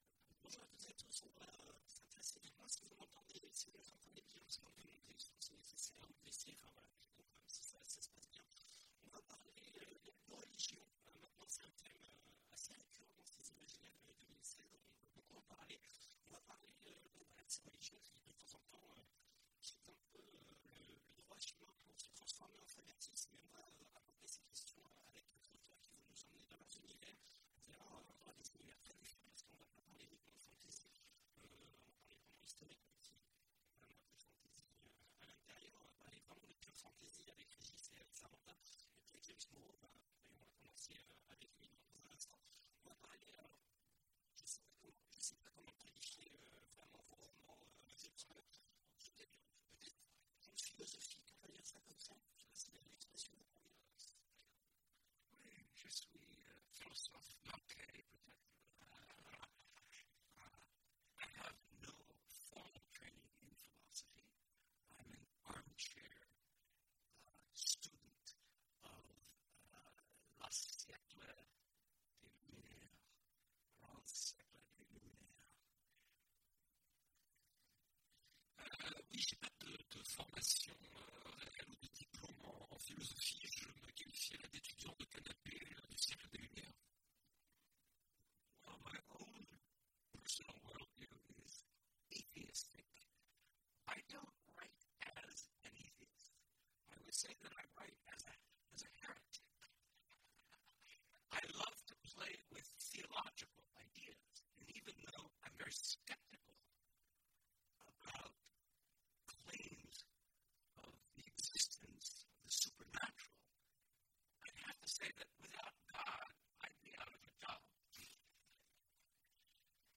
Imaginales 2016 : Conférence Quand la religion…